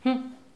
hum_03.wav